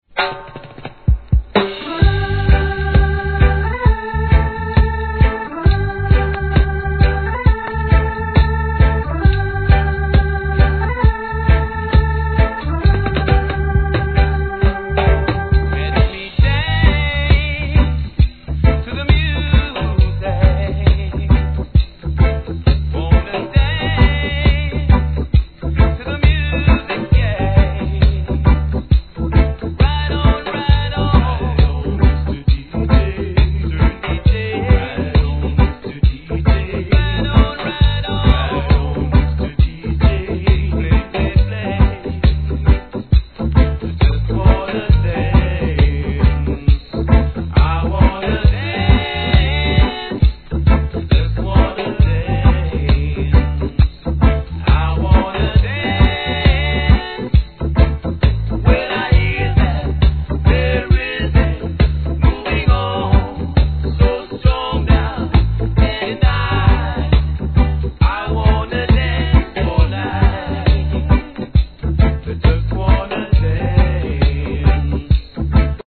REGGAE
後半はシンセのBREAKからDeeJayへ!!